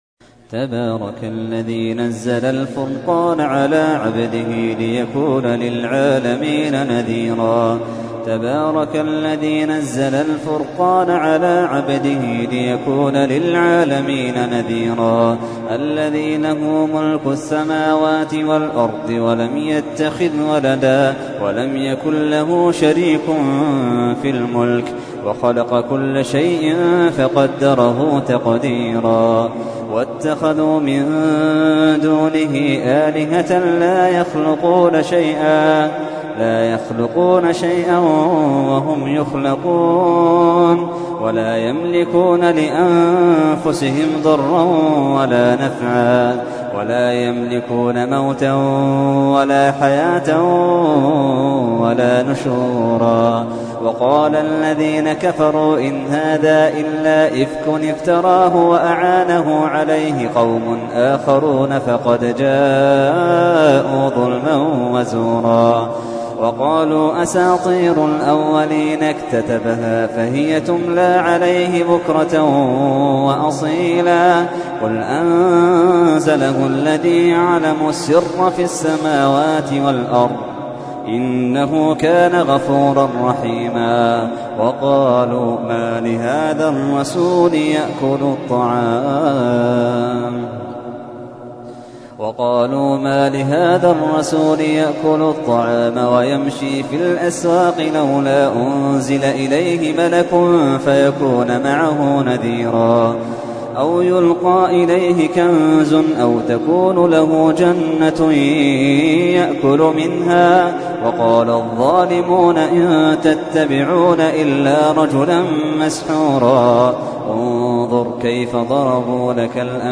تحميل : 25. سورة الفرقان / القارئ محمد اللحيدان / القرآن الكريم / موقع يا حسين